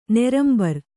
♪ nerambar